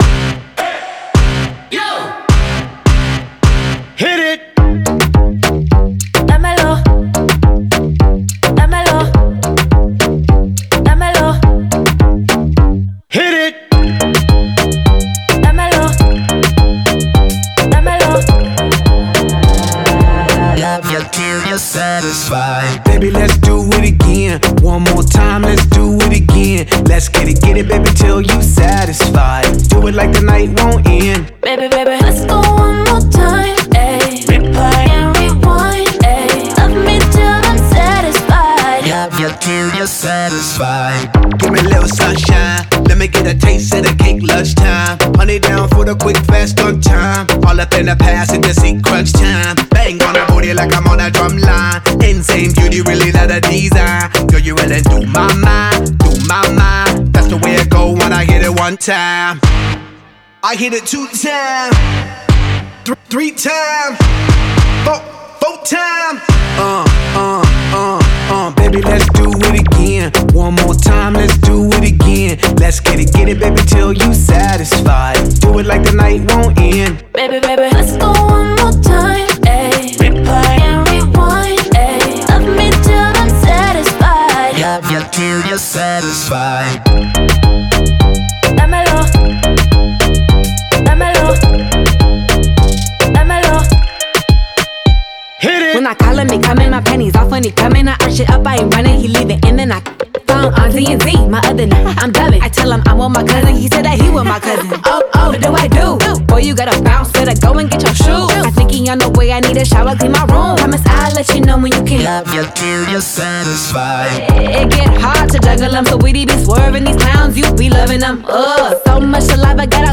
это энергичная поп- и хип-хоп композиция